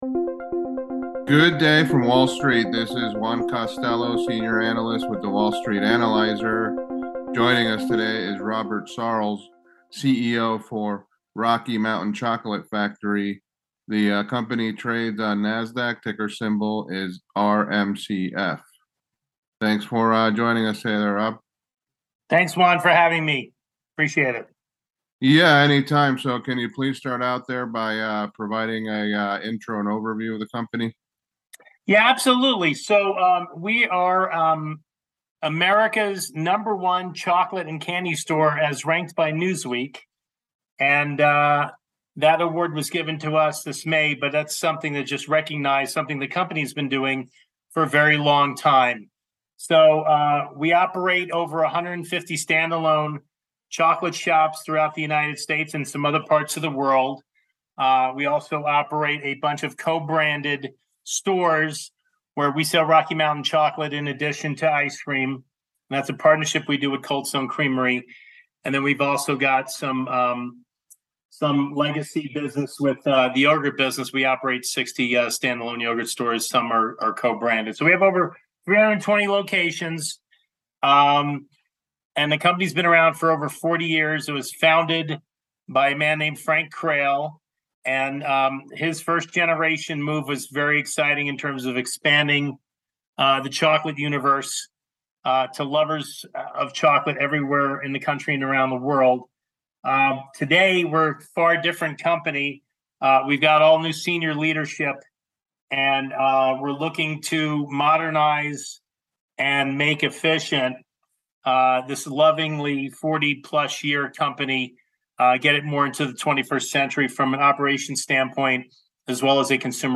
Wall Street Analyzer Interview